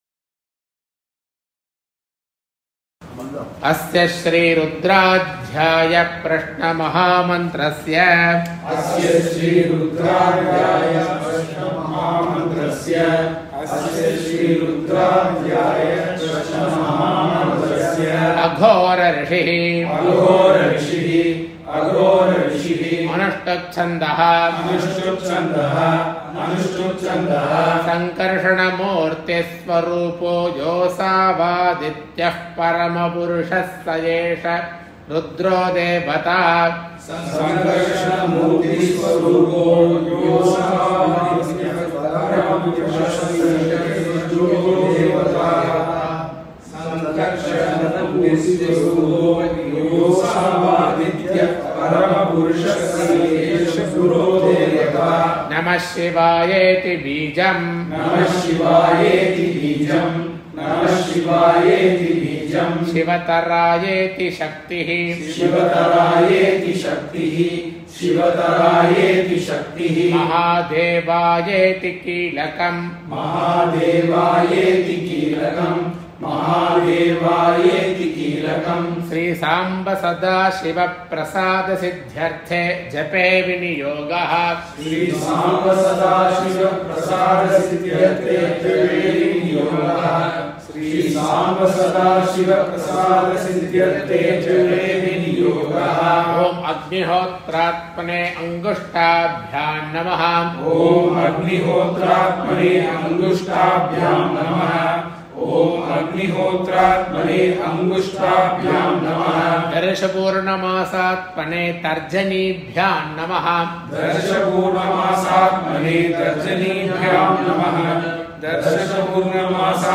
chanting
Line by Line with repeat